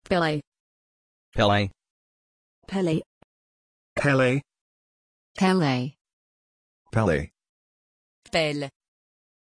Aussprache von Pelle
pronunciation-pelle-en.mp3